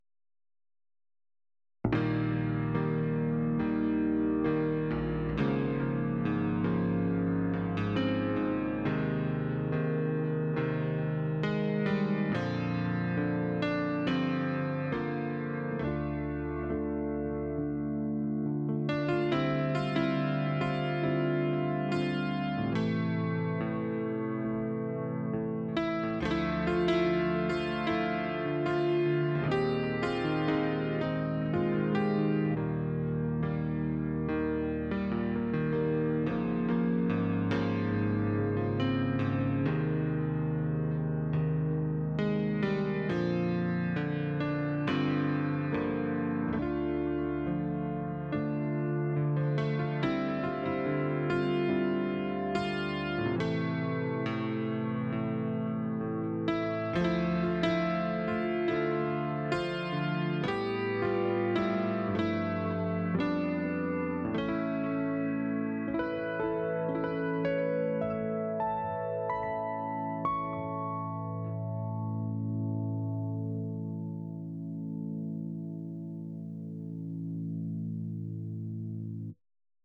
Here’s my best attempt so far at replicating the sound.